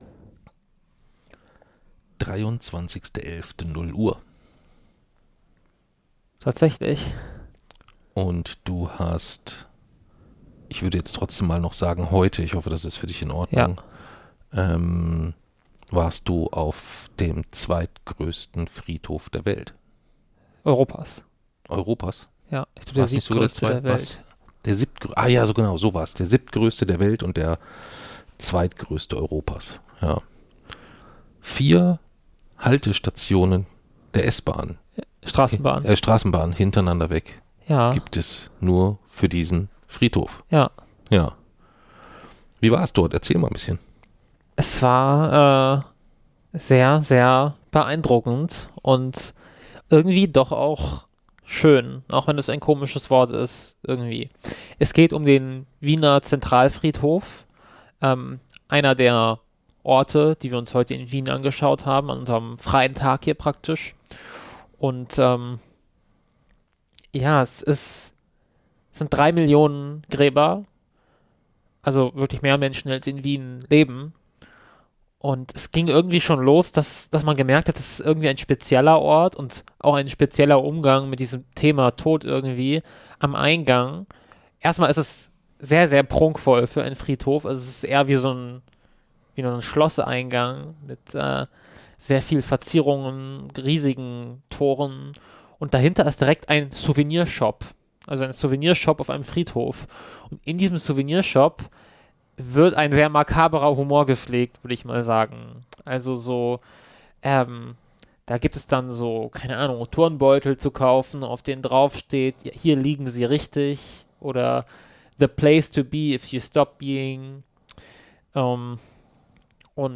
Noch vor Ort haben wir eine erste Episode zu unserem Besuch in Wien aufgenommen.